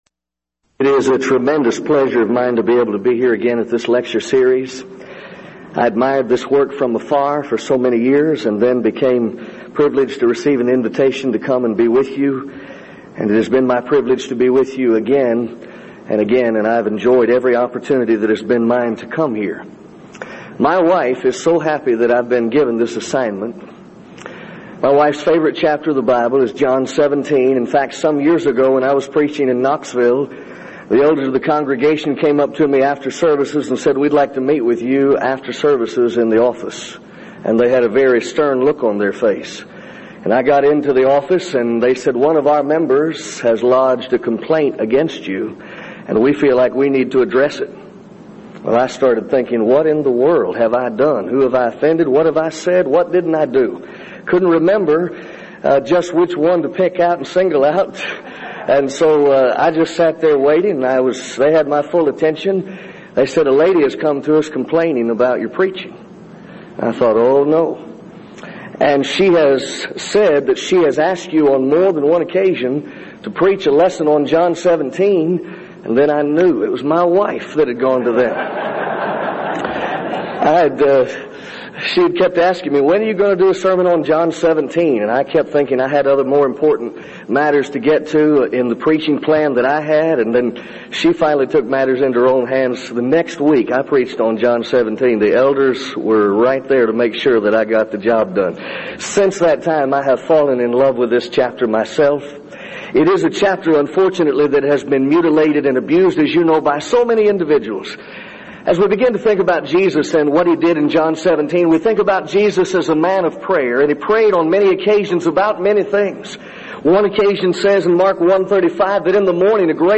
Title: DISCUSSION FORUM: What is the nature of the unity for which Christ prayed? John 17:20-21
Event: 1999 Denton Lectures
lecture